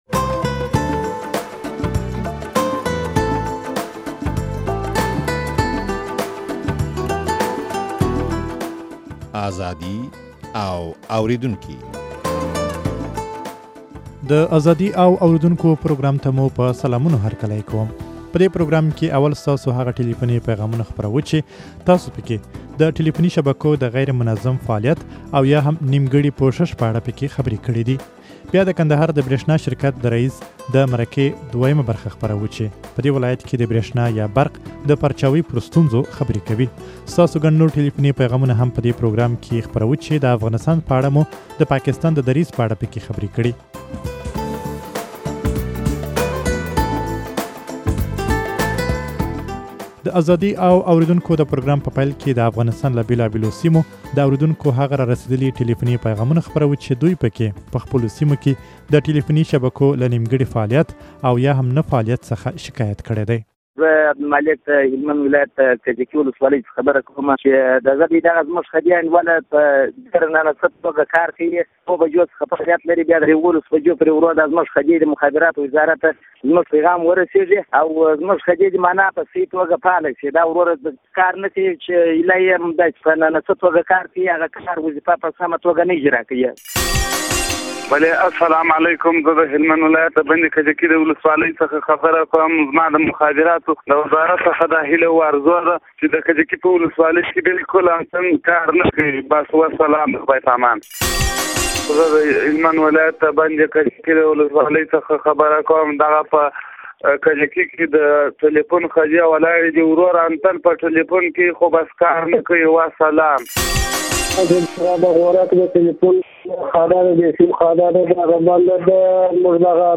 په دې پروګرام کې اول ستاسو هغه ټليفوني پيغامونه خپروو، چې تاسو په کې د ټليفوني شبکو د غيرمنظم فعاليت او يا هم نيمګړي پوښښ په اړه خبرې کړې دي.